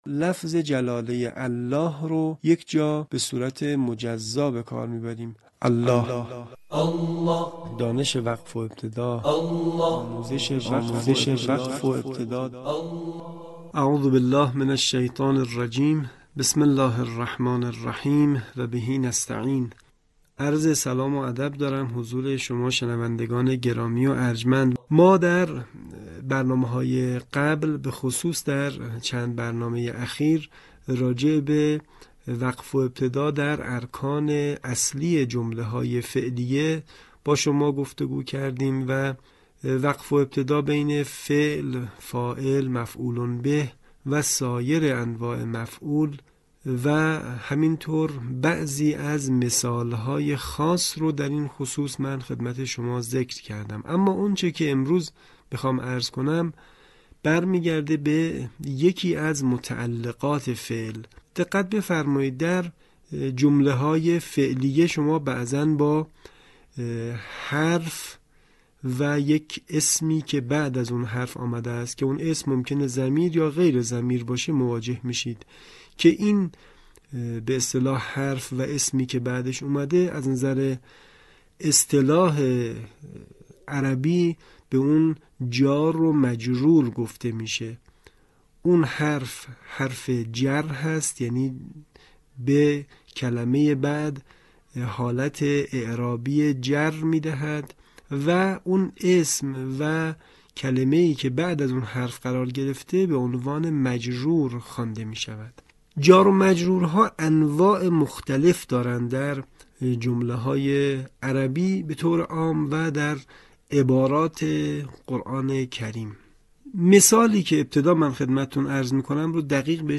یکی از مهم‌ترین سیاست‌های رسانه ایکنا نشر مبانی آموزشی و ارتقای سطح دانش قرائت قرآن مخاطبان گرامی است. به همین منظور مجموعه آموزشی شنیداری(صوتی) قرآنی را گردآوری و برای علاقه‌مندان بازنشر می‌کند.